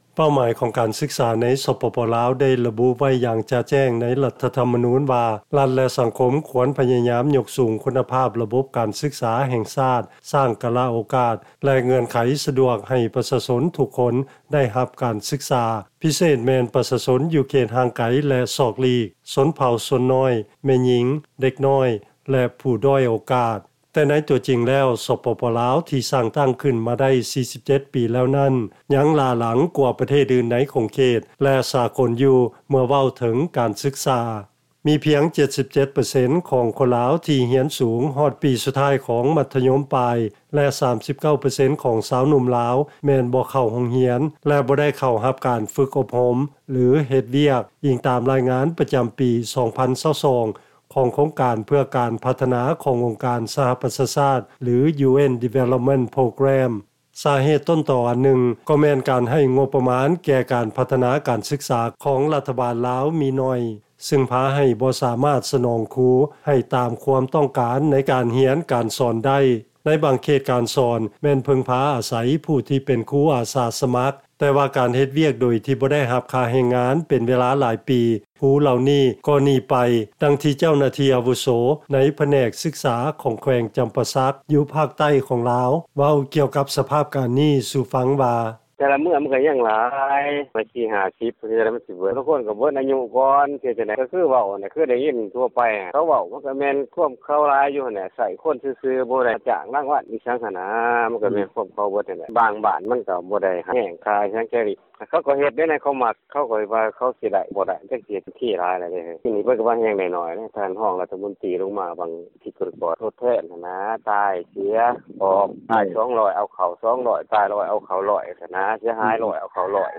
ຟັງລາຍງານ ລາວເປັນປະເທດນຶ່ງ ທີ່ມີຄຸນນະພາບການສຶກສາຕໍ່າທີ່ສຸດ ໃນຂົງເຂດເອເຊຍຕາເວັນອອກສຽງໃຕ້ ຍ້ອນຫຼາຍສາເຫດ